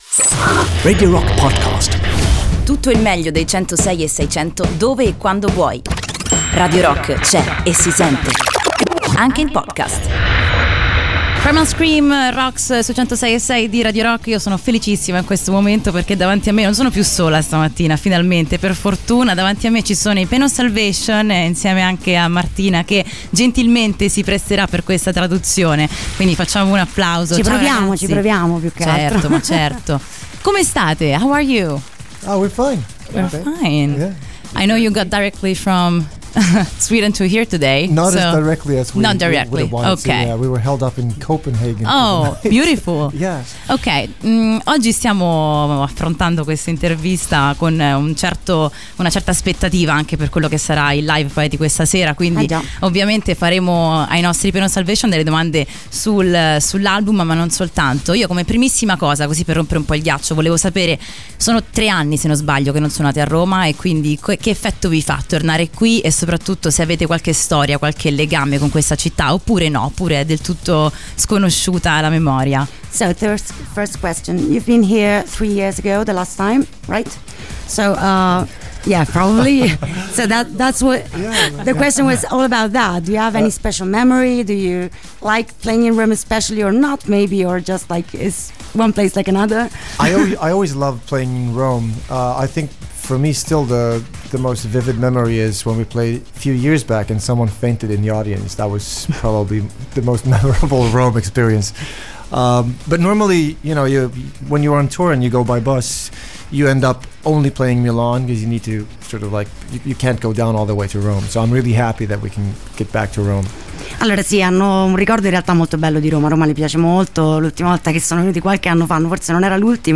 Intervista: Pain Of Salvation (16-06-18)